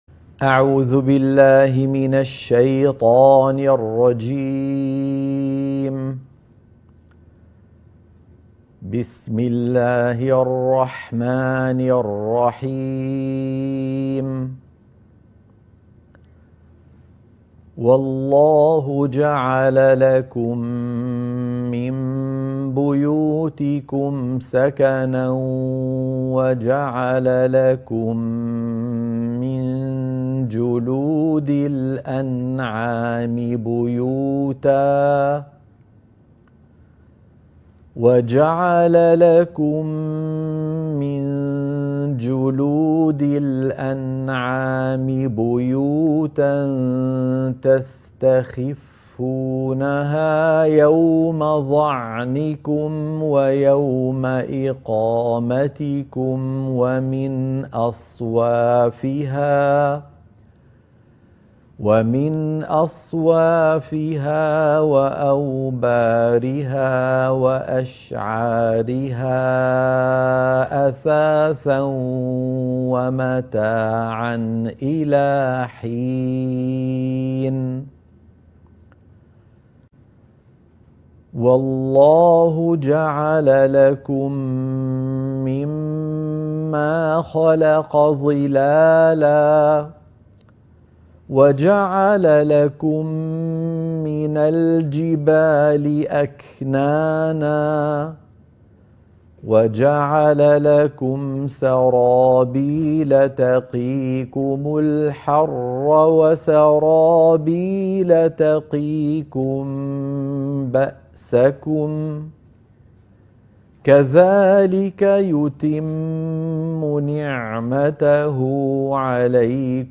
تلاوة نموذجية للآيات 80 - 87 من سورة النحل - الشيخ أيمن سويد